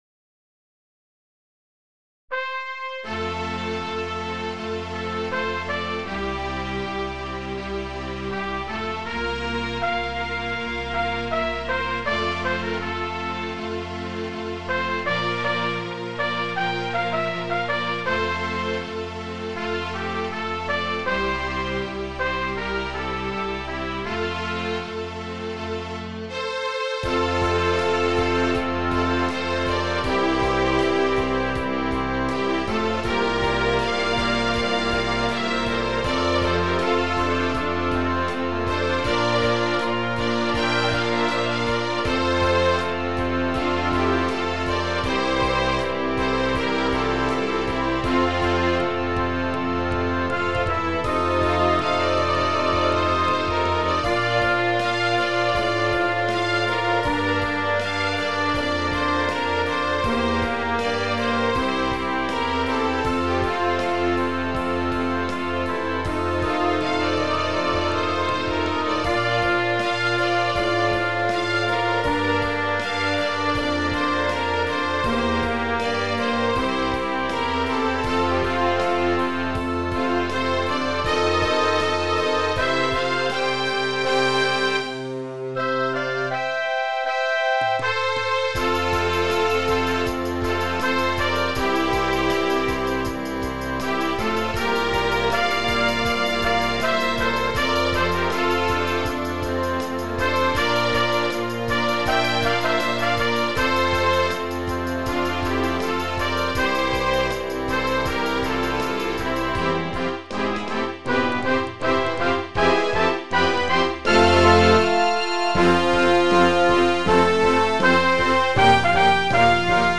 An Orchestra arrangement